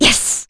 Chrisha-Vox_Happy4.wav